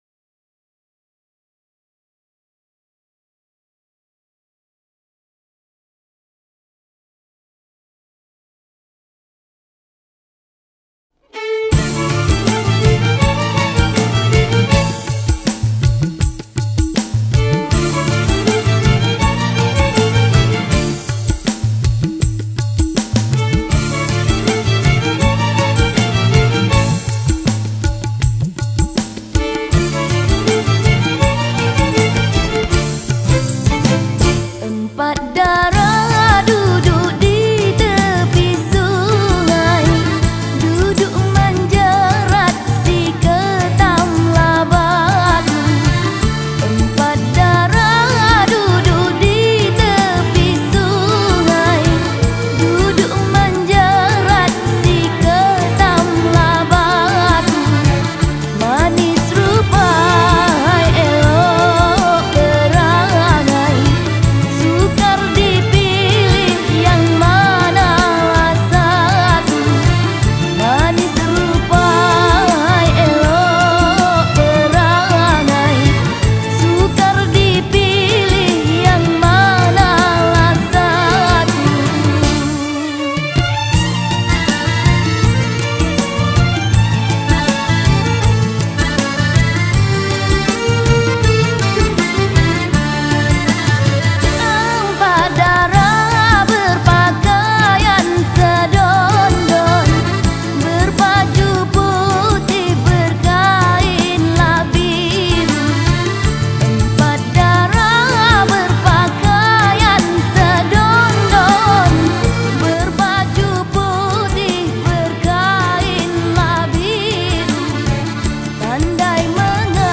Malay Songs
Skor Angklung